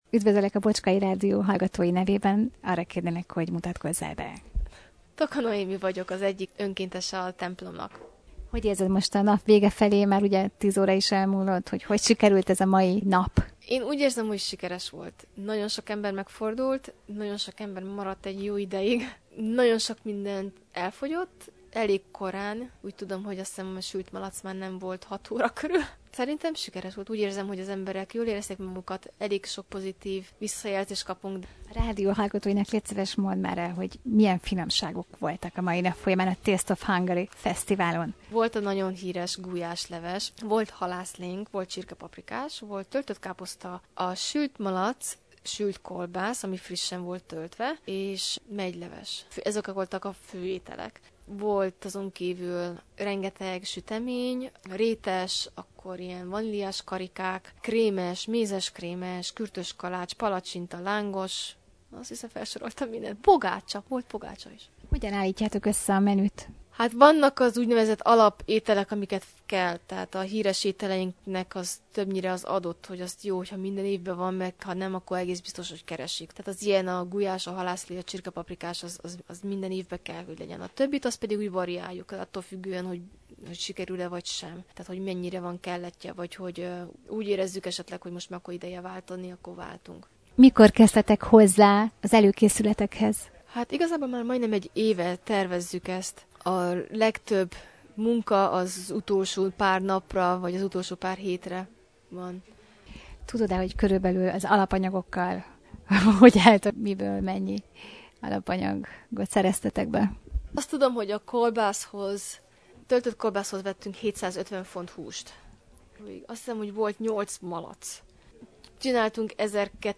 Rövid interjút kértünk tőle, hogy foglalja össze a fesztivál eredményeit.